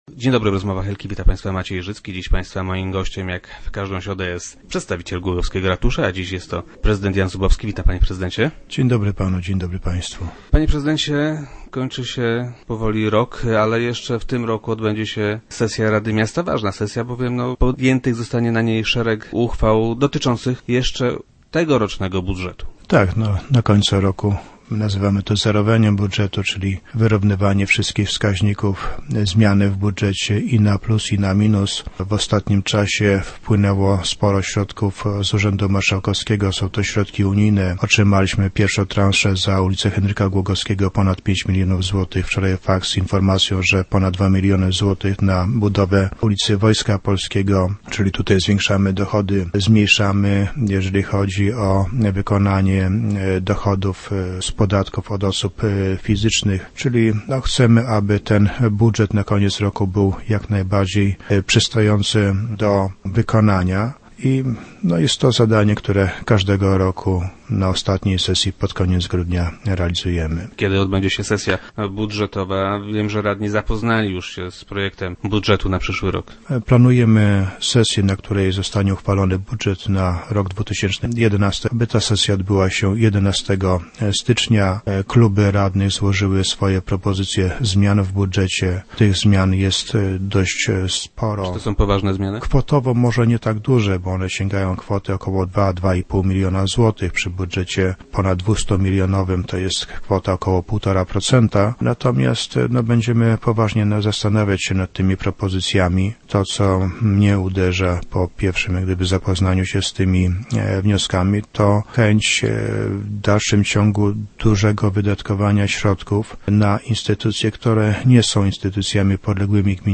- Część z nich zostanie uwzględniona. Niektóre jednak nas zaskakują - twierdzi prezydent Jan Zubowski, który był dziś gościem Rozmów Elki.